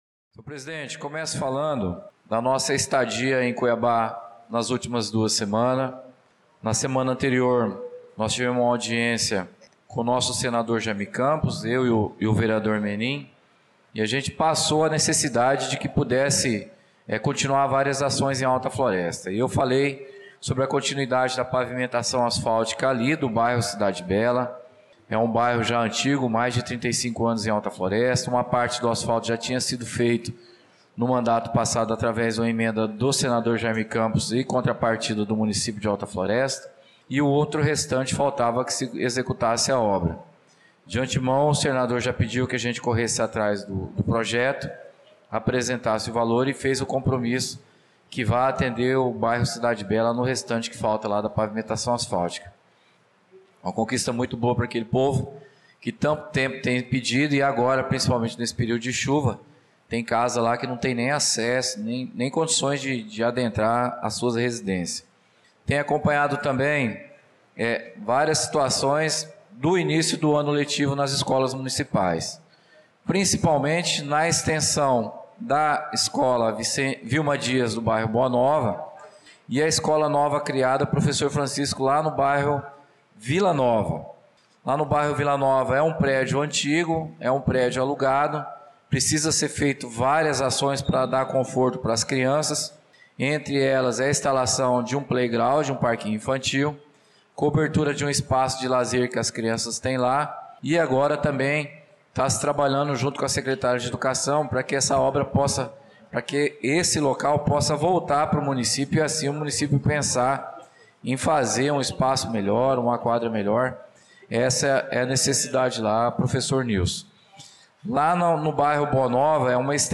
Pronunciamento do vereador Claudinei de Jesus na Sessão Ordinária do dia 18/02/2025